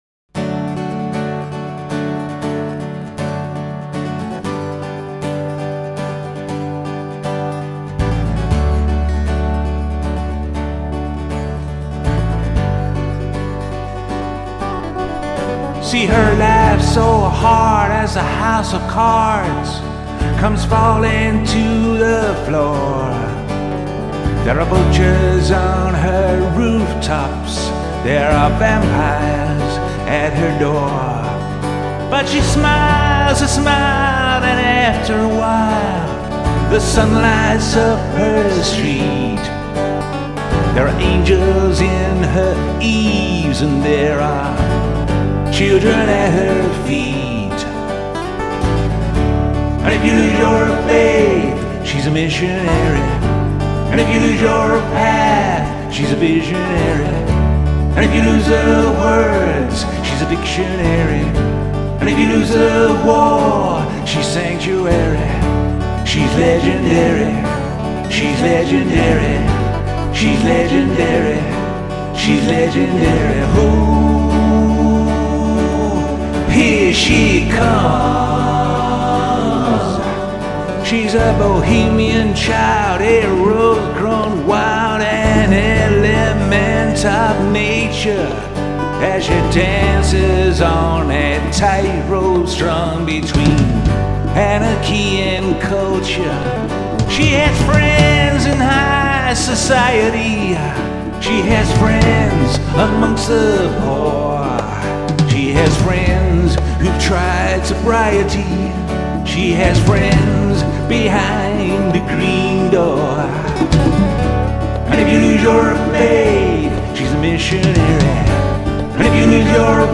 nice acoustic feel
a noticeable French influence